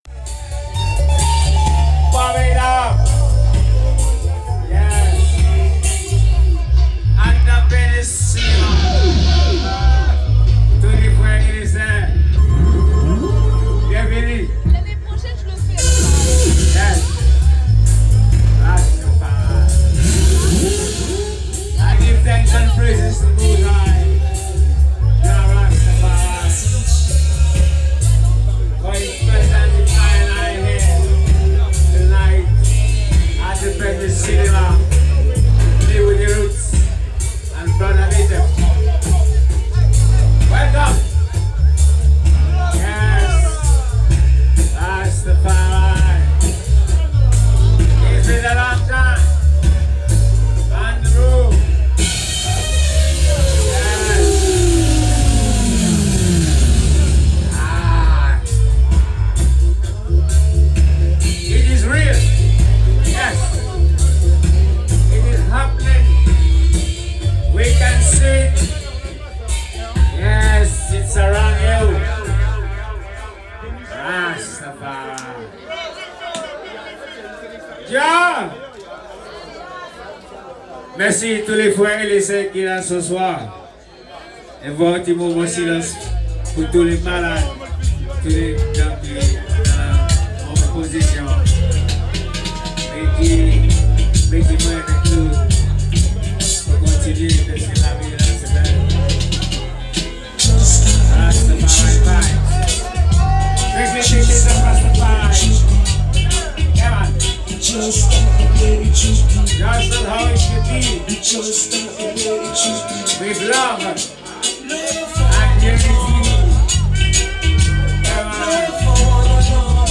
The bass can be high sometimes!